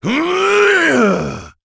Bernheim-Vox_Casting2.wav